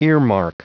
Prononciation du mot earmark en anglais (fichier audio)
Prononciation du mot : earmark